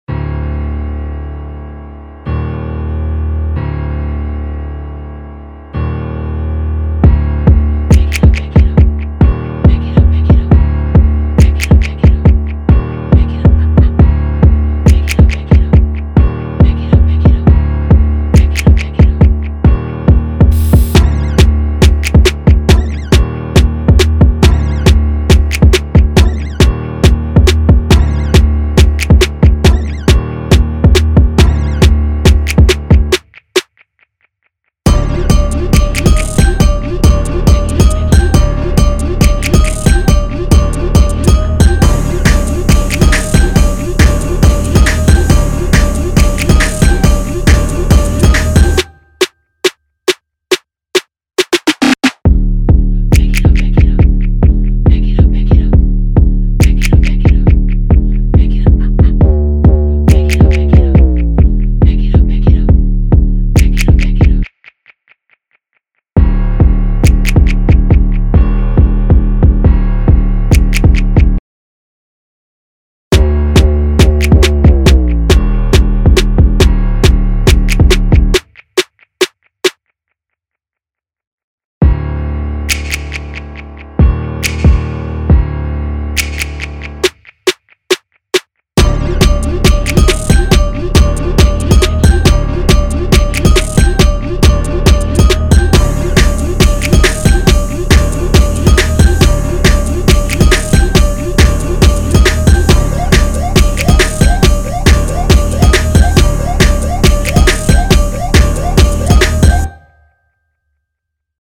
2023 in New Jersey Club Instrumentals